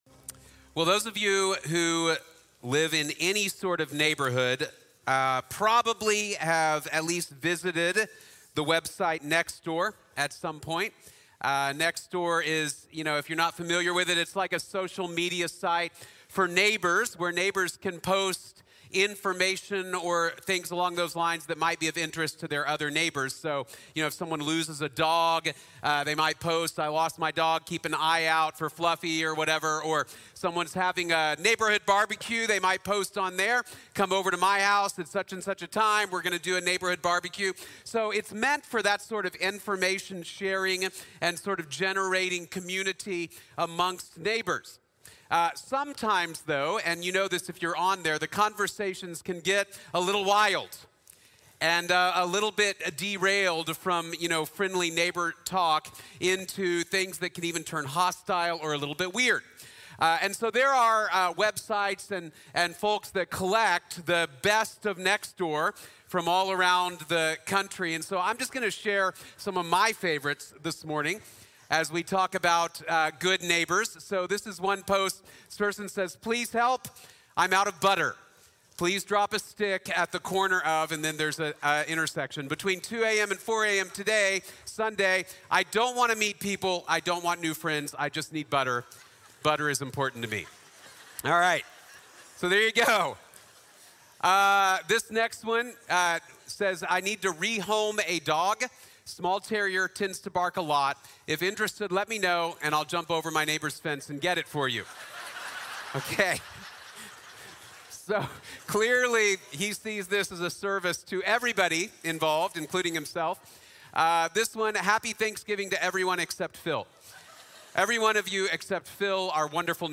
Good Neighbors | Sermon | Grace Bible Church